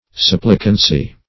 Supplicancy \Sup"pli*can*cy\, n.
supplicancy.mp3